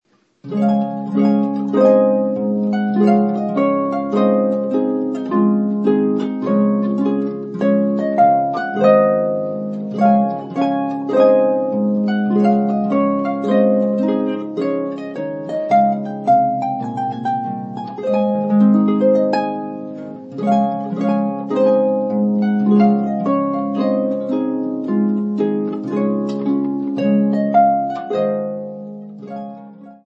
beautiful harp music